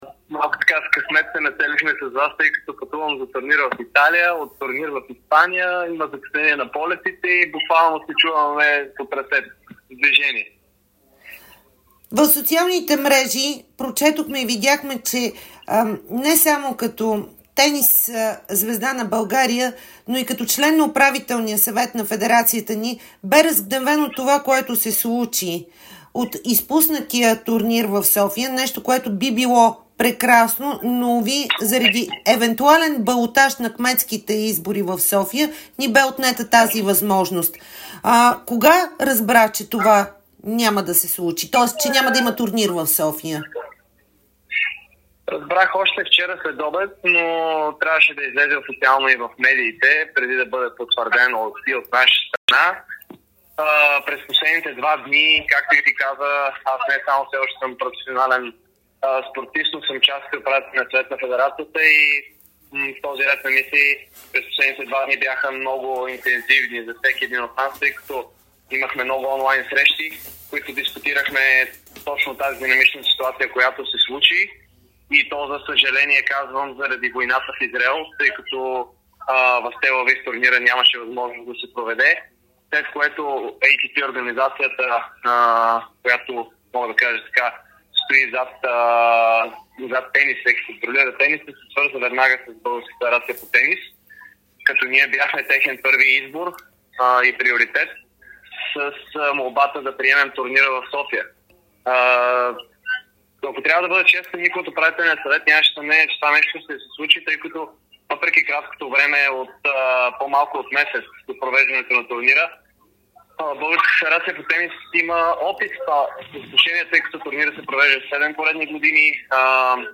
Националът за Купа „Дейвис“ Димитър Кузманов говори специално пред Дарик радио по горещата тема – провала на Sofia Open.